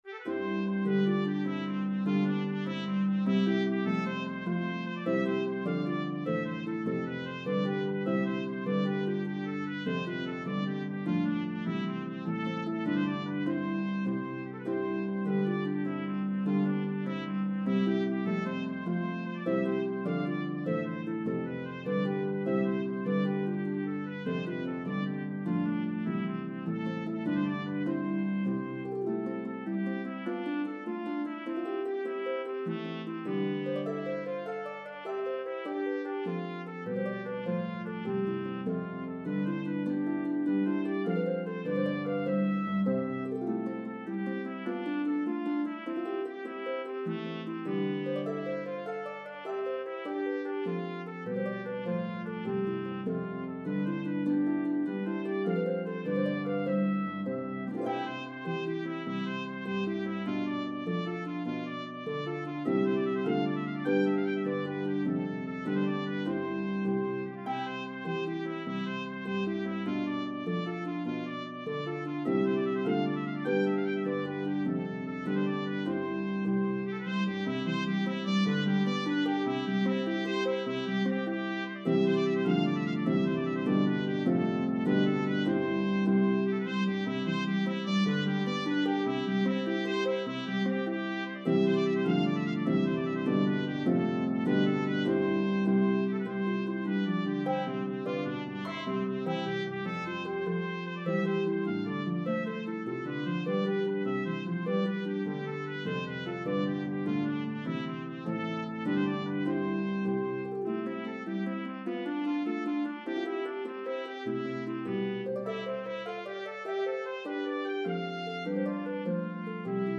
A spirited Irish Jig